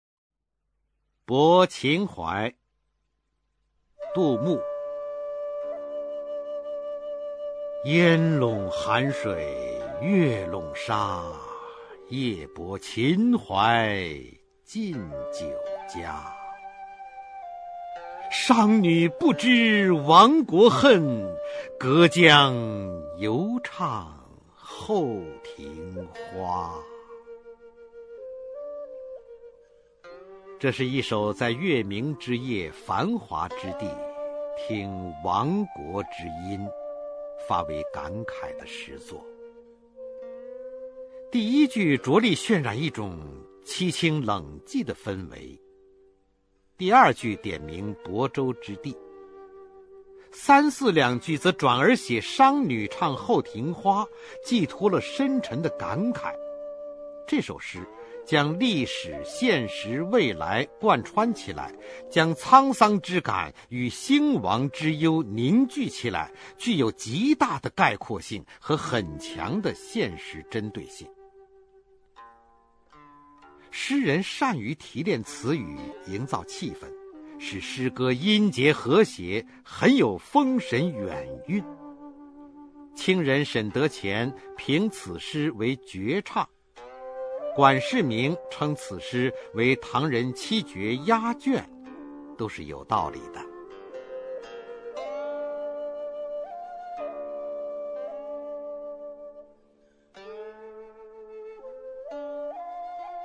[隋唐诗词诵读]杜牧-泊秦淮（男） 古诗文诵读